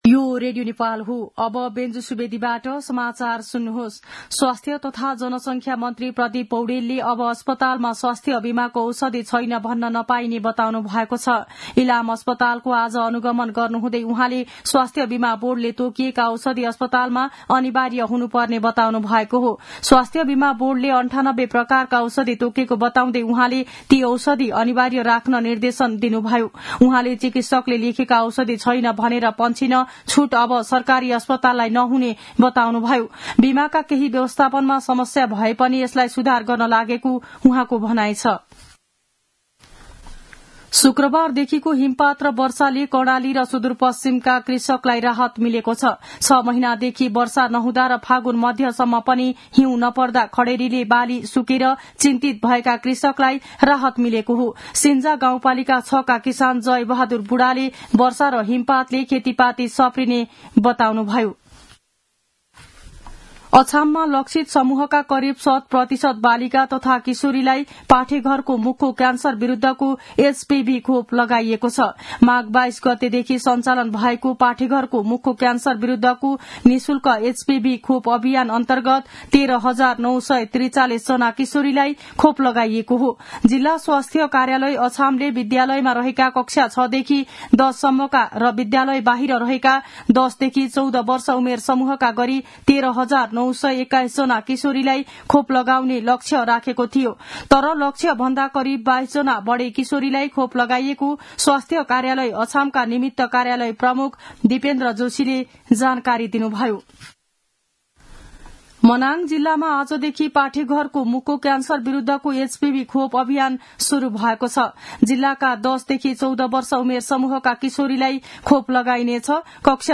मध्यान्ह १२ बजेको नेपाली समाचार : १९ फागुन , २०८१
12-am-news-.mp3